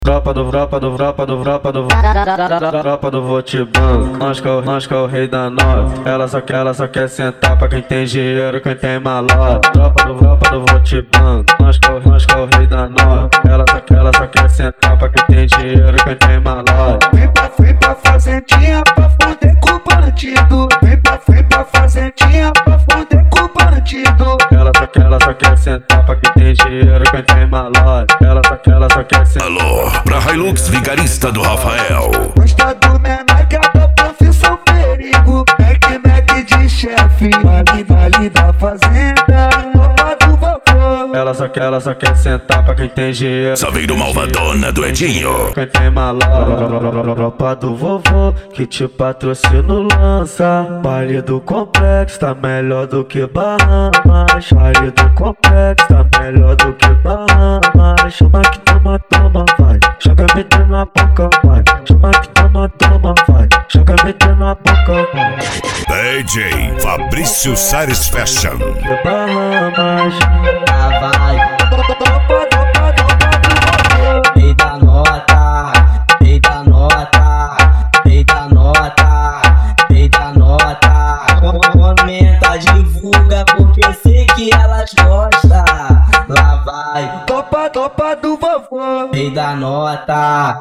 Bass
Funk